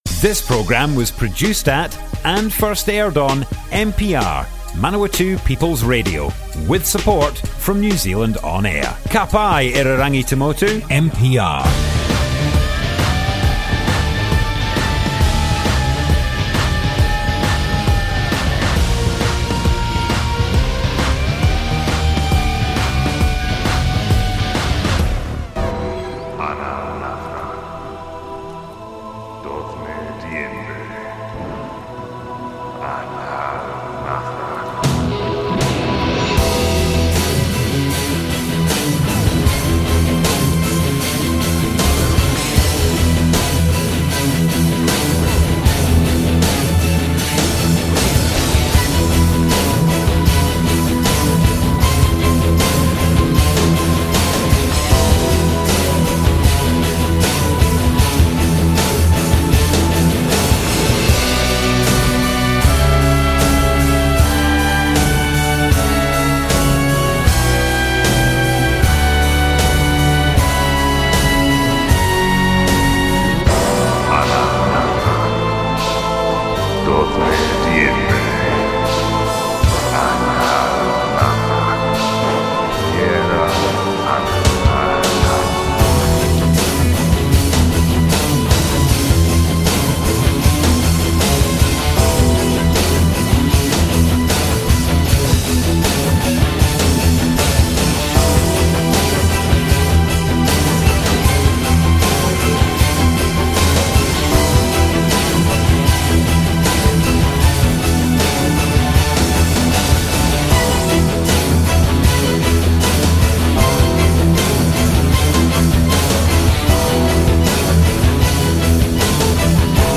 Hosted by local wrestling fans
An hour of wrasslin' tunes